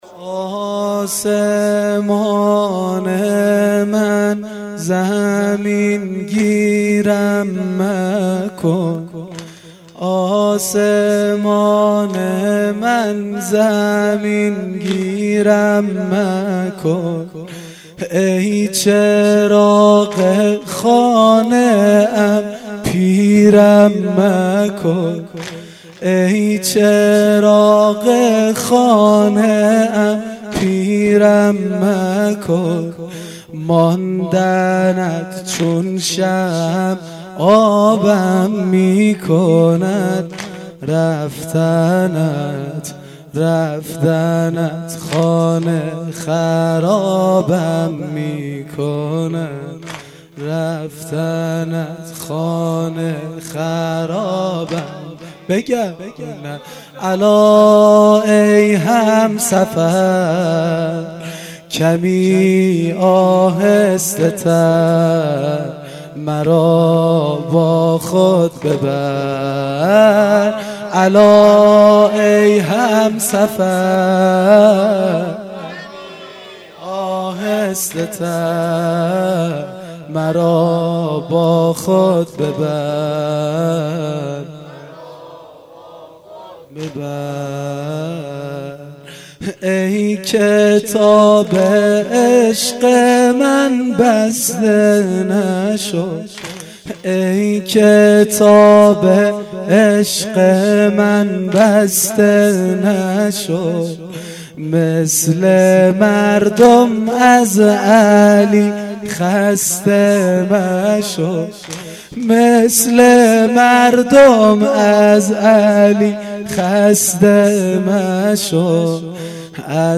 واحدسنگین شب سوم فاطمیه دوم